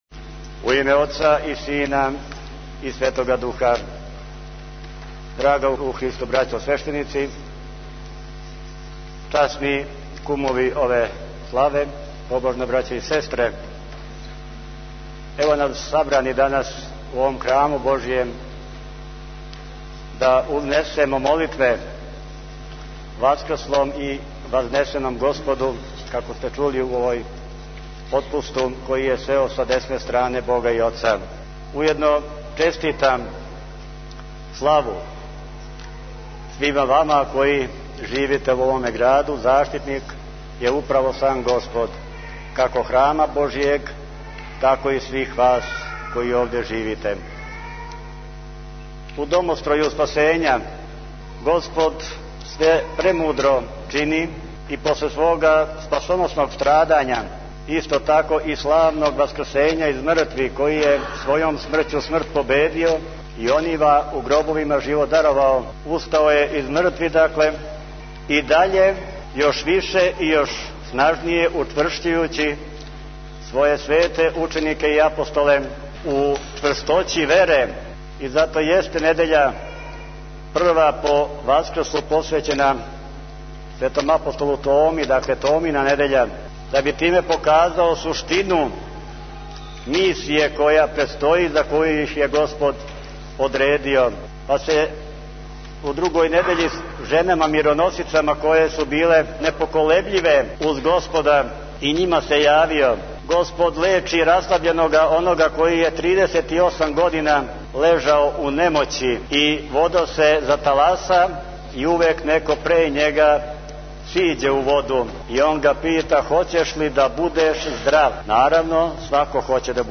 одржао беседу којом је позвао верни народ суботички да што чешће долази свој свети храм.